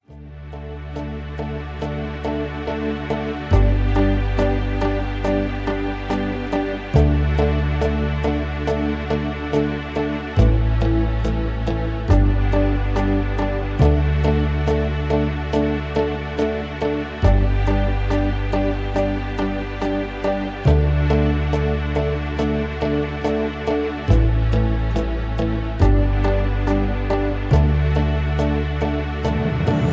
Backing Tracks for Professional Singers.